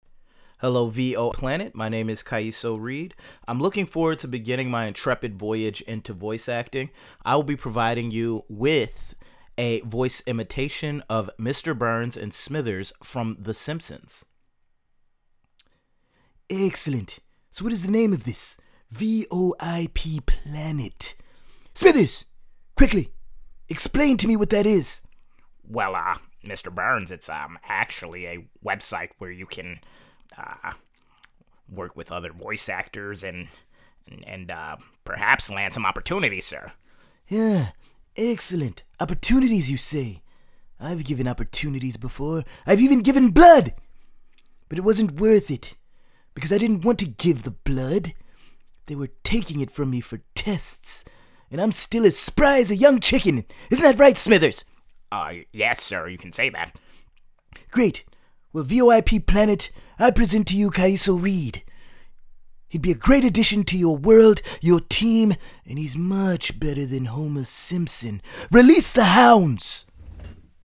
Mr. Burns and Smithers (The Simpsons)
Animated, Serious, Southern, English,
Young Adult
Character Voice